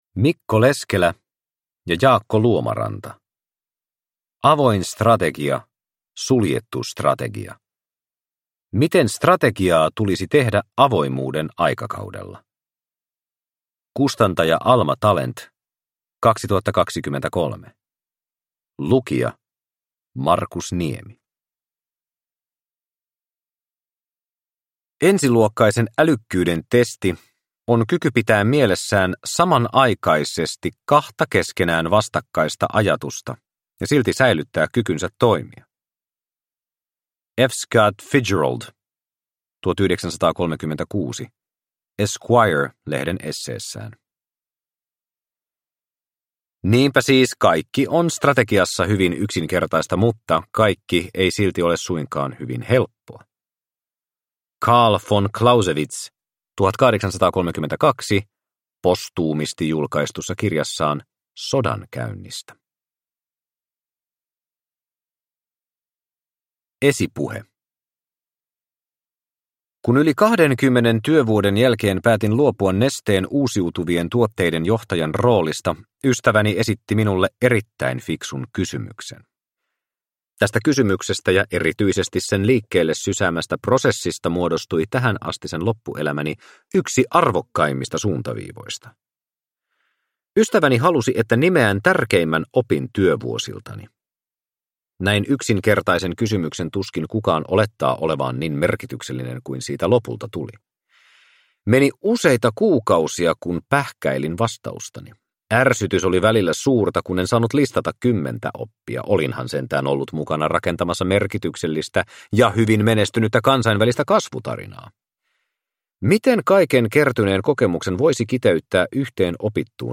Avoin strategia / Suljettu strategia – Ljudbok – Laddas ner
Produkttyp: Digitala böcker